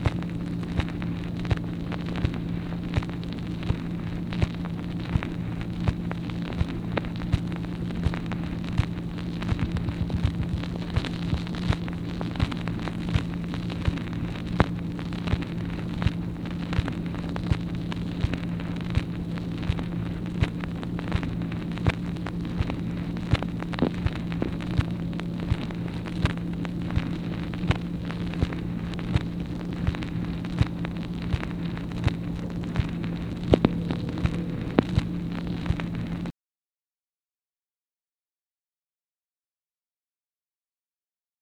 MACHINE NOISE, April 9, 1964 | Miller Center
Secret White House Tapes | Lyndon B. Johnson Presidency